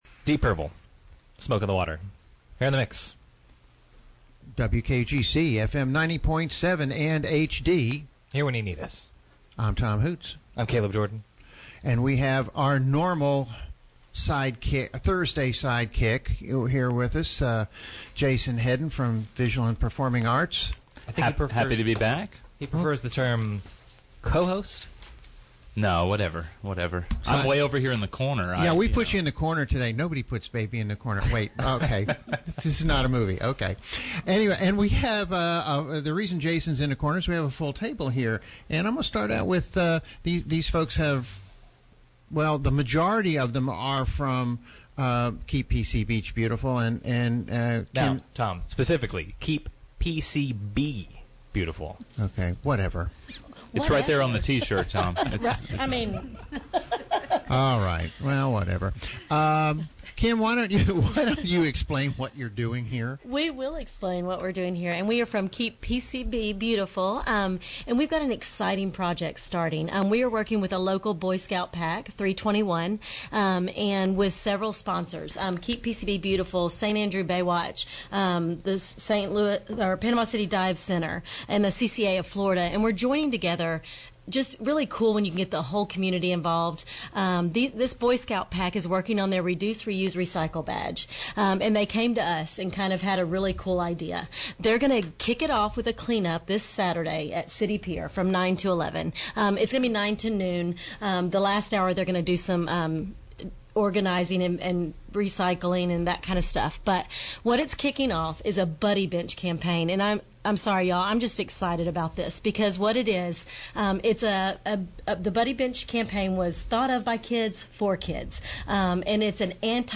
WKGC Studio